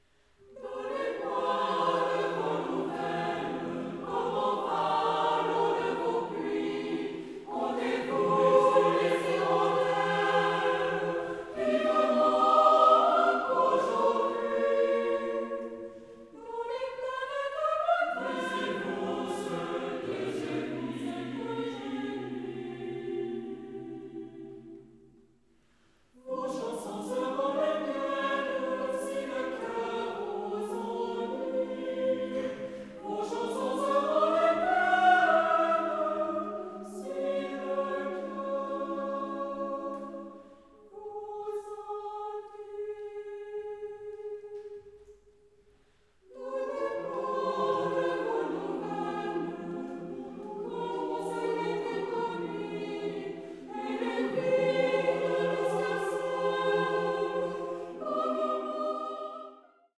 pour choeur mixte et récitant